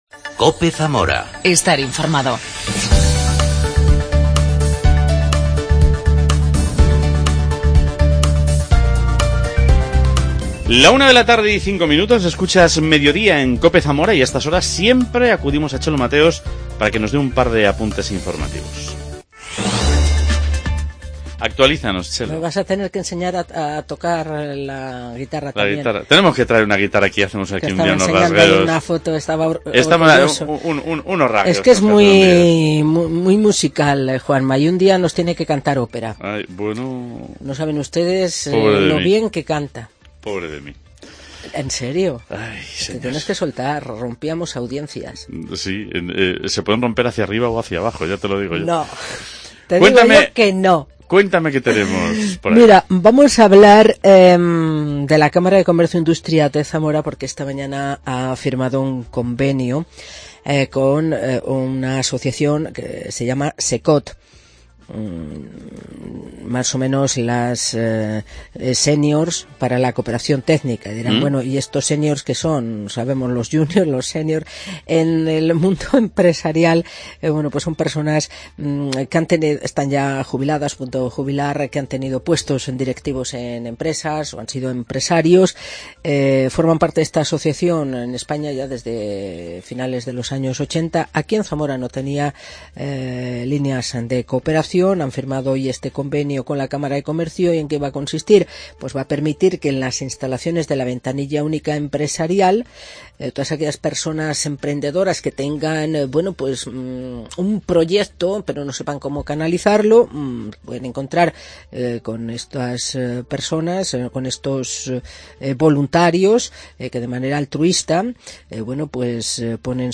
acompañado por bandas sonoras de su juventud.